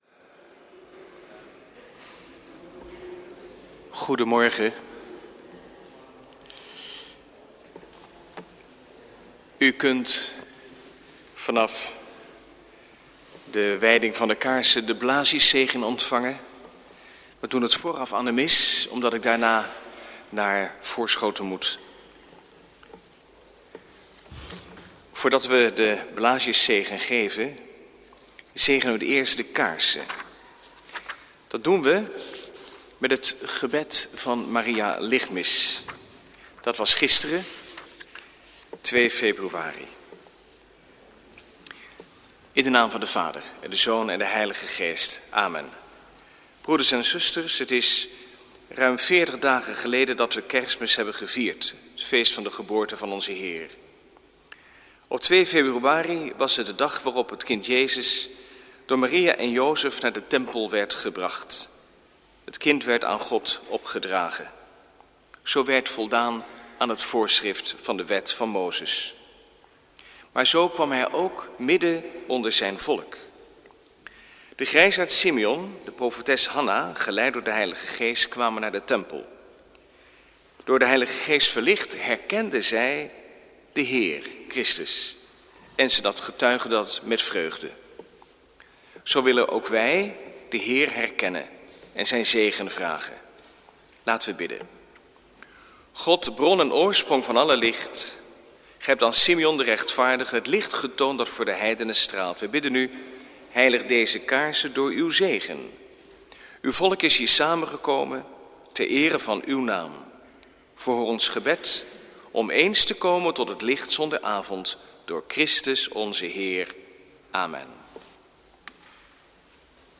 Lezingen
Eucharistieviering beluisteren vanuit de H. Joannes de Doper te Katwijk (MP3)